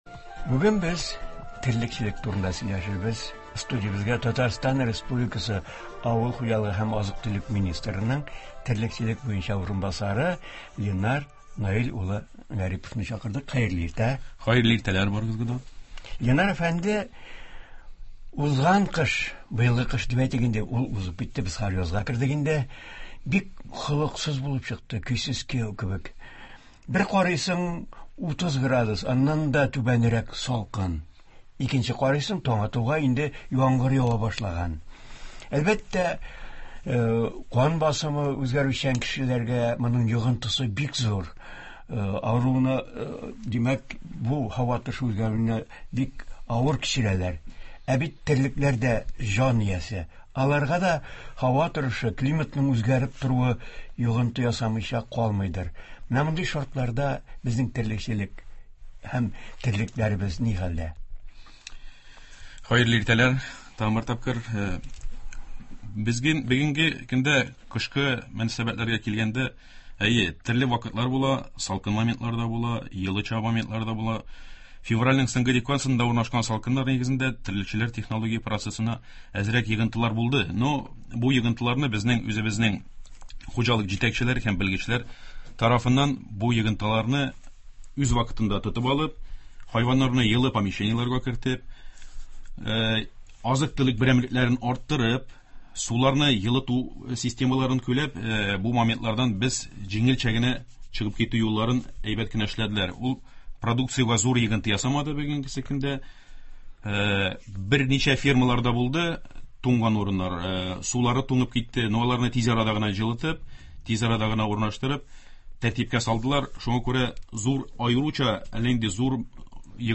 Быел кыш үзенчәлекле: зәмһәрир салкыннарны яңгырлы көннәр алыштыра. Мондый катлаулы һава торышы терлекләр сәламәтлегенә һәм продуктлылыгына ничек йогынты ясый? Болар хакында турыдан-туры эфирда Татарстан авыл хуҗалыгы һәм азык-төлек министрының терлекчелек буенча урынбасары Ленар Гарипов сөйли һәм тыңлаучылар сорауларына җавап бирә.